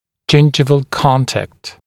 [‘ʤɪnʤɪvəl ‘kɔntækt] [ʤɪn’ʤaɪvəl][‘джиндживэл ‘контэкт] [джин’джайвэл]десневой контакт